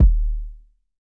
Kick (4).wav